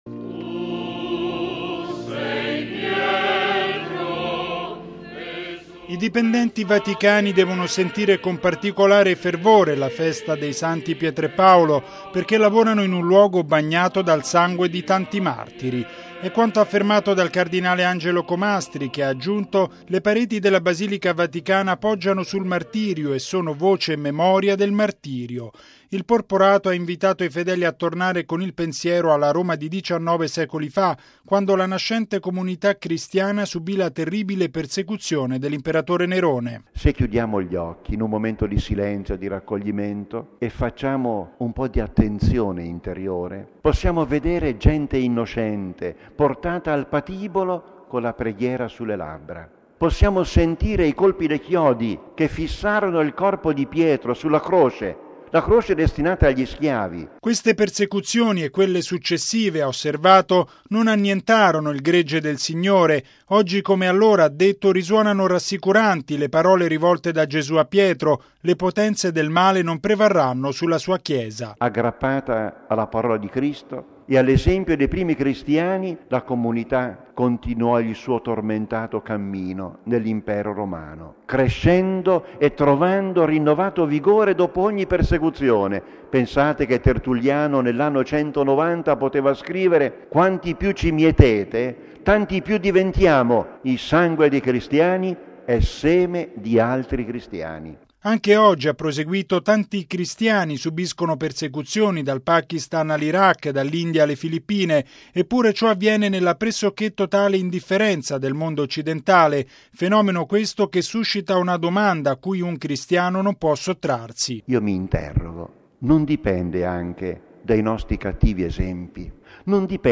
◊   Il ricordo del martirio di Pietro deve spingere i cristiani a ritrovare gioia e coerenza nel vivere la propria fede: è l’esortazione del cardinale Angelo Comastri nella Messa tenutasi stamani nella Basilica Vaticana per i dipendenti vaticani in preparazione alla solennità dei Santi Pietro e Paolo.
Canti